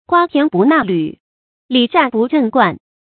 注音：ㄍㄨㄚ ㄊㄧㄢˊ ㄅㄨˋ ㄣㄚˋ ㄌㄩˇ ，ㄌㄧˇ ㄒㄧㄚˋ ㄅㄨˋ ㄓㄥˋ ㄍㄨㄢ